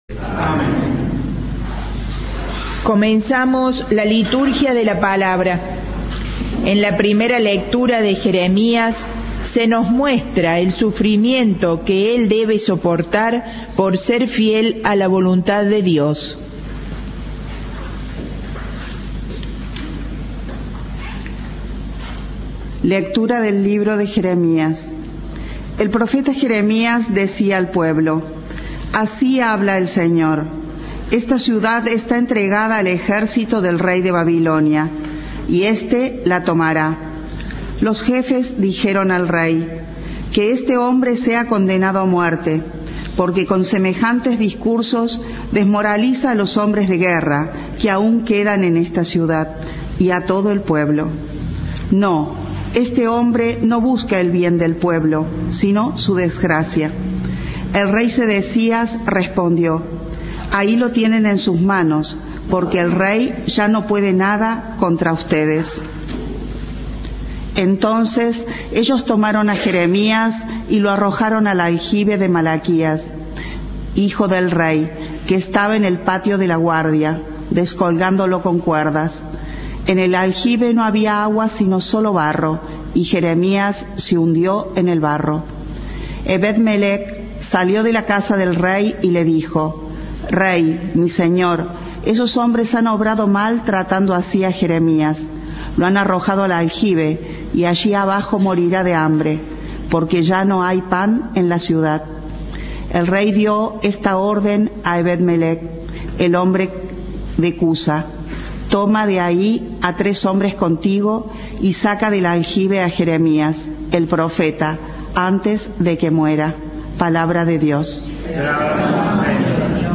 Monseñor Rossi reflexionó sobre los desafíos de la fe cristiana - Santa Misa - Cadena 3 Argentina